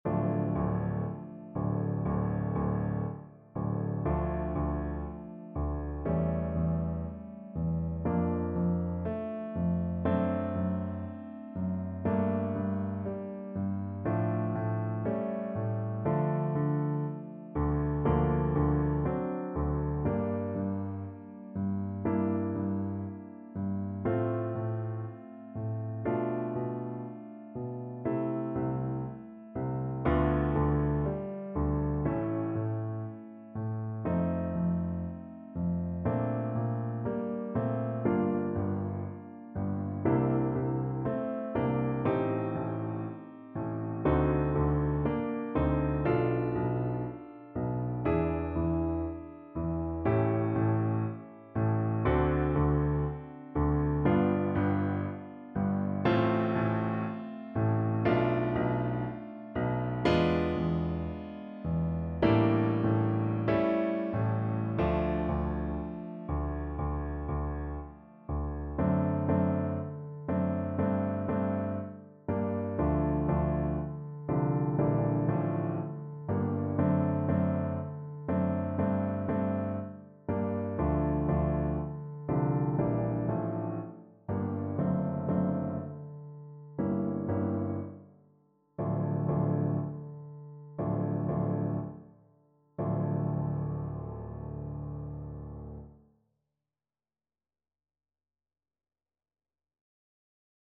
Play (or use space bar on your keyboard) Pause Music Playalong - Piano Accompaniment Playalong Band Accompaniment not yet available transpose reset tempo print settings full screen
Molto Moderato = 60
D minor (Sounding Pitch) (View more D minor Music for Bassoon )
Classical (View more Classical Bassoon Music)